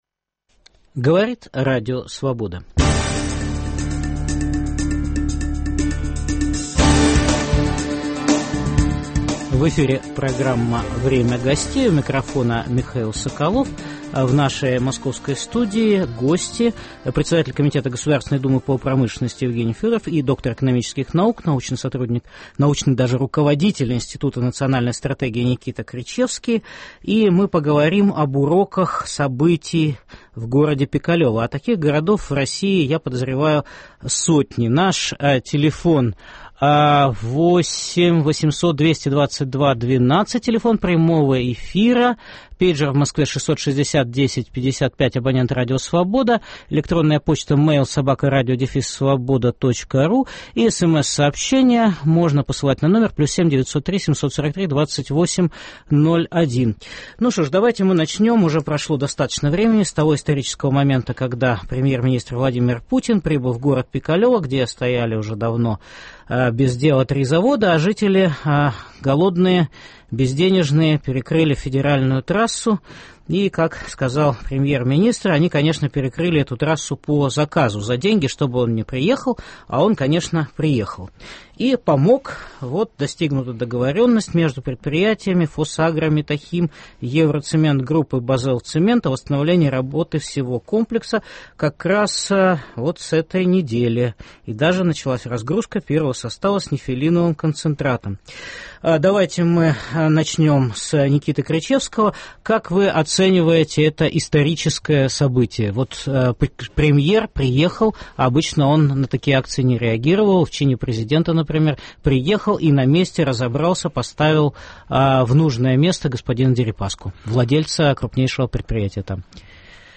В программе: об уроках событий в Пикалево дискутируют председатель комитета Государственной Думы России по промышленности Евгений Федоров